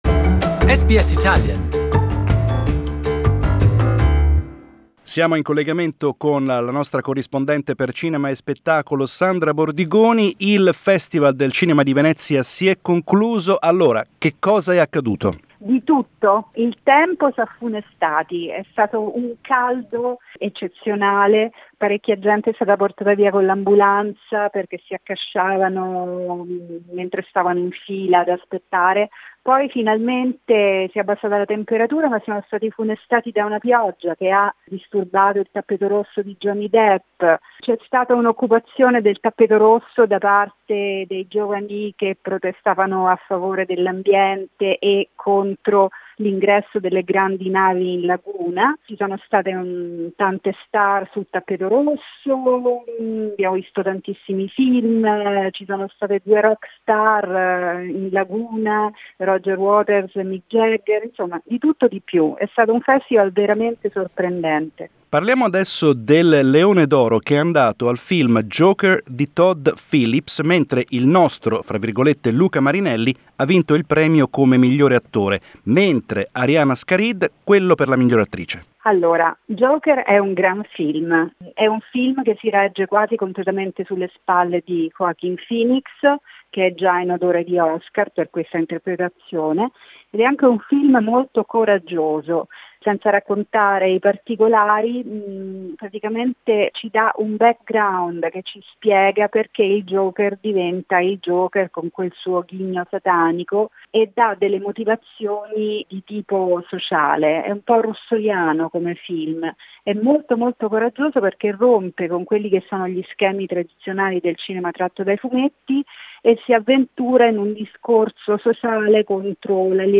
Our movie buff and globe-trotting reporter